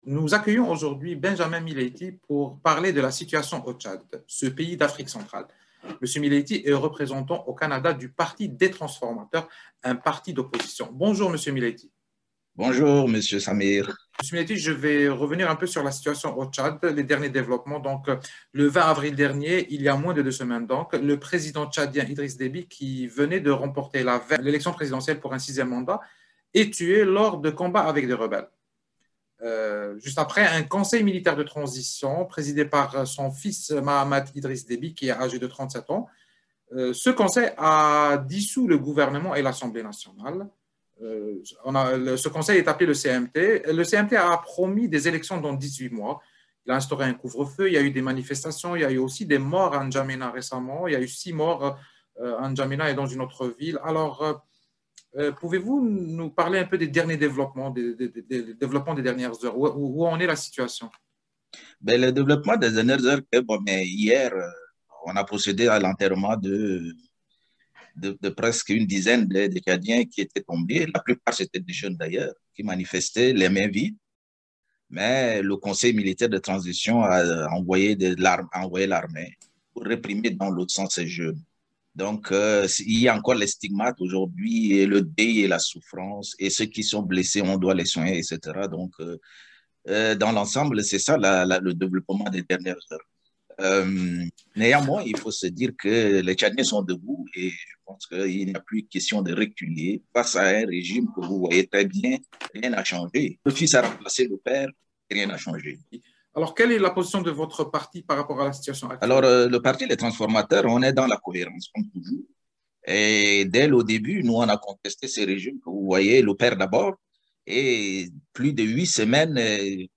en entrevue avec Radio Canada International